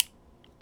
R - Foley 262.wav